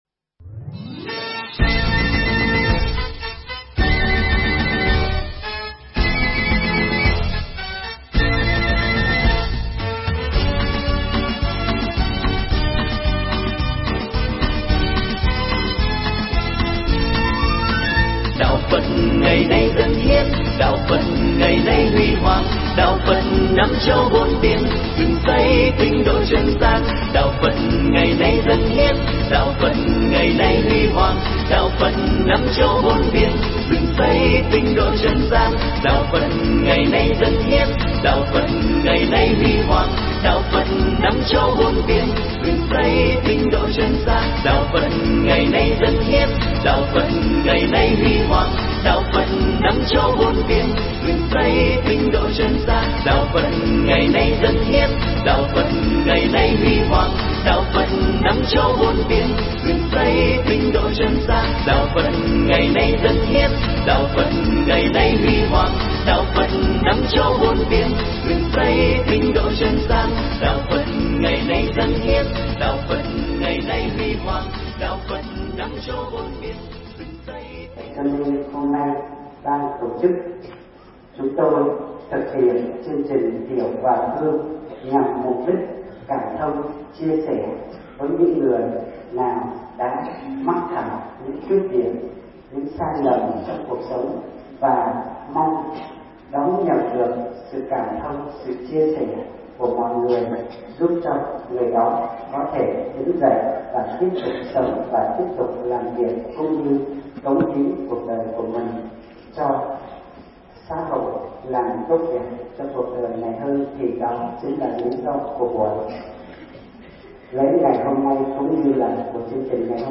Pháp thoại Hiểu và thương
giảng tại chùa Viên Quang – Bắc Ninh